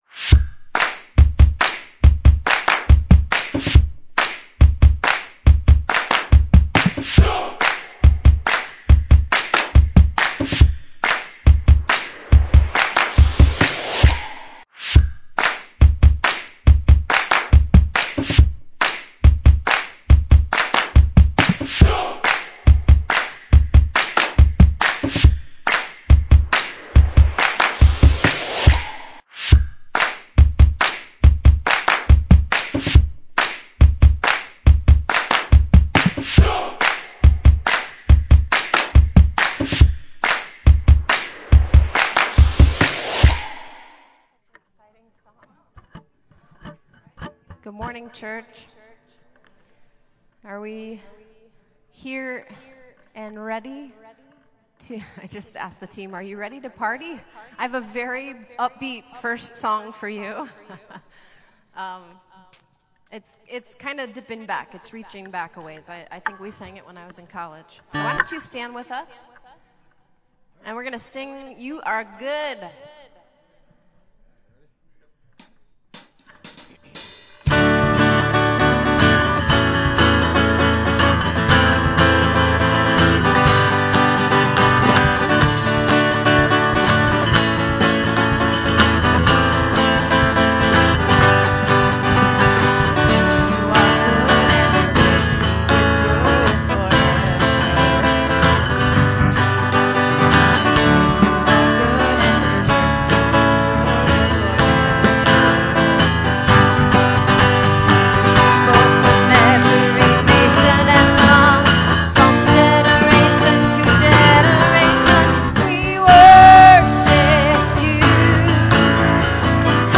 Praise Worship